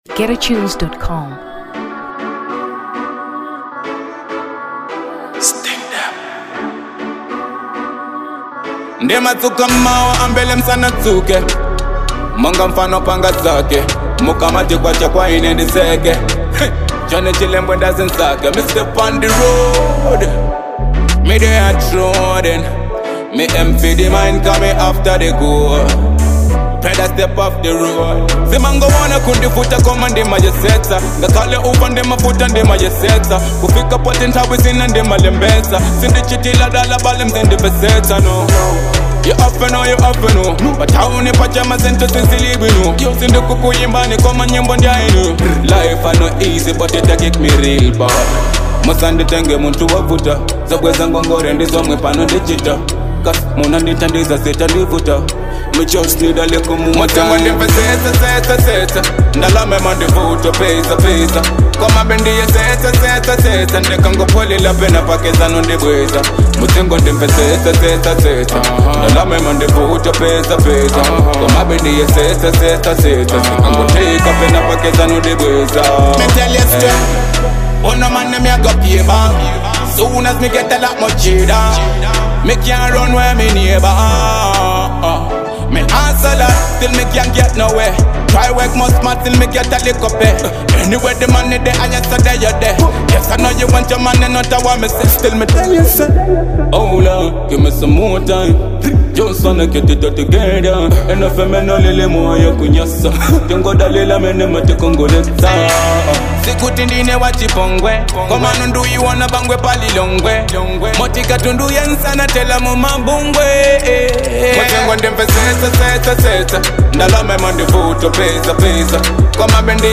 Dancehall 2023 Malawi